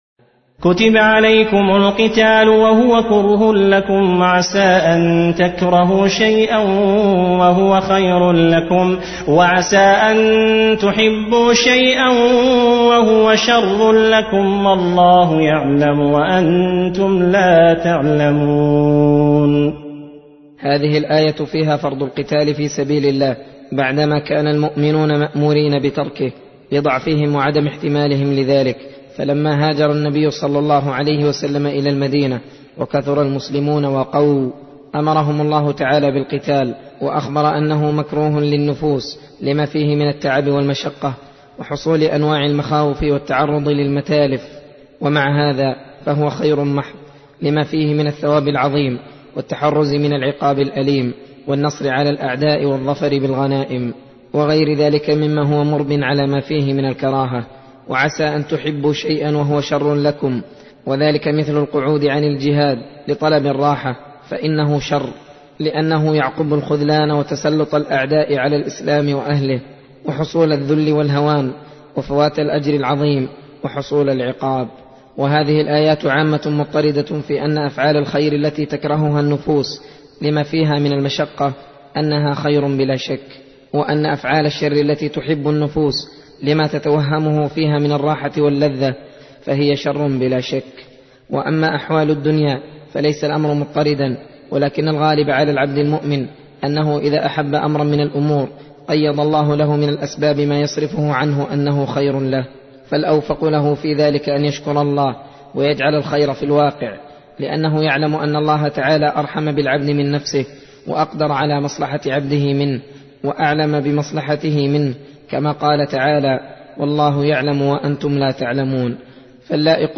درس (19) : تفسير سورة البقرة : (216-225)